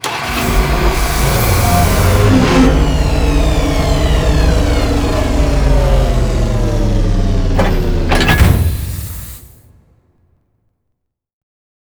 EngineStop.wav